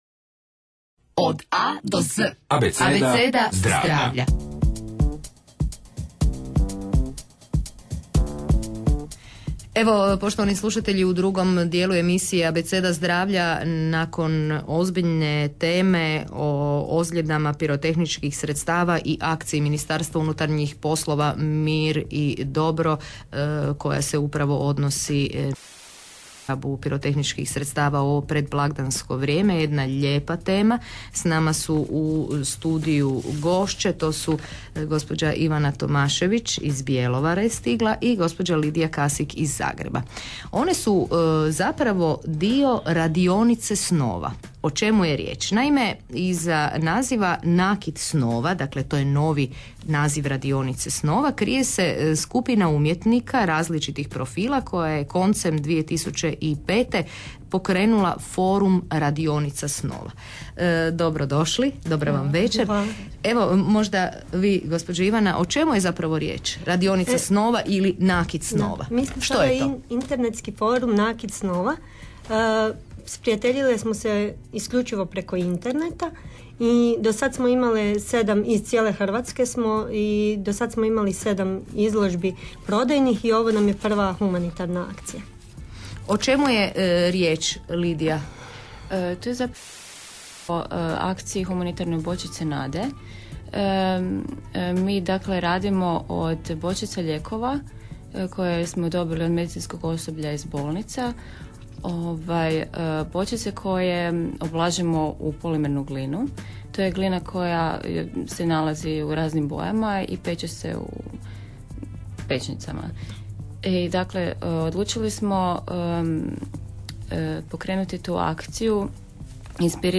Objavljene radijske snimke "Nakita Snova"